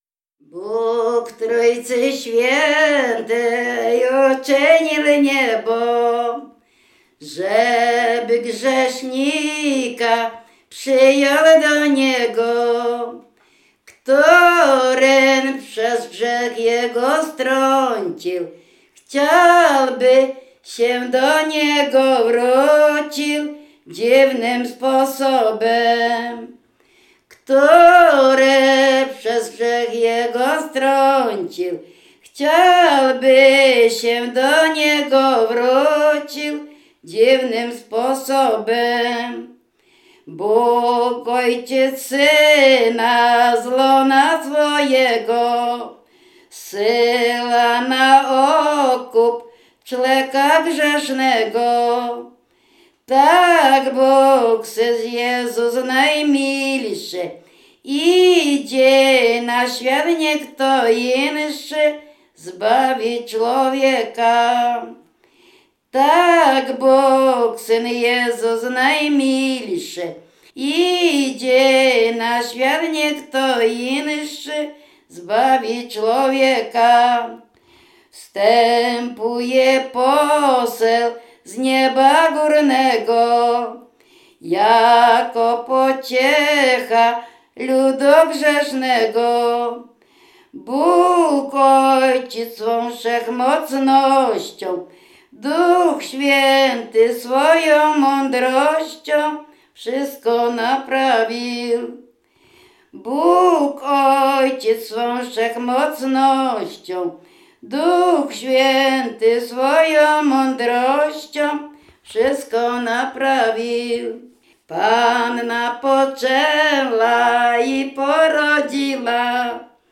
województwo dolnośląskie, powiat lwówecki, gmina Mirsk, wieś Mroczkowice
W wymowie Ł wymawiane jako przedniojęzykowo-zębowe;
e (é) w końcu wyrazu zachowało jego dawną realizację jako i(y)
Kolęda